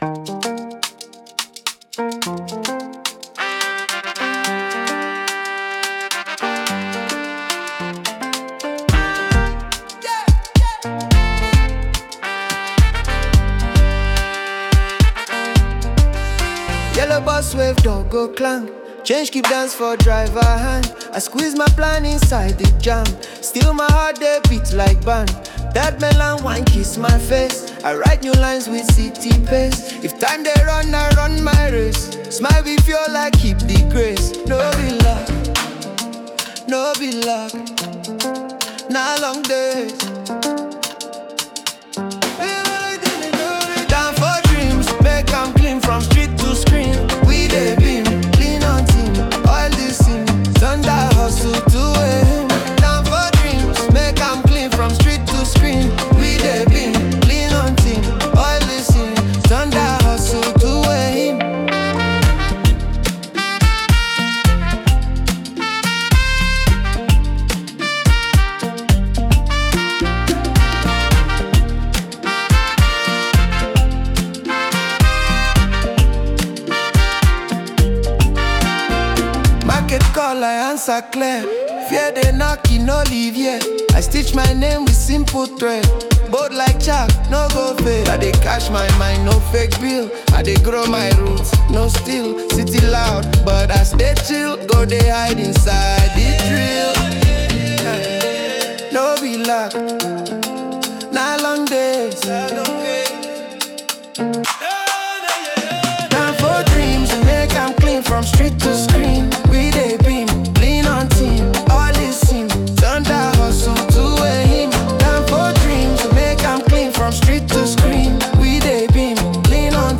Afrobeats 2025 Non-Explicit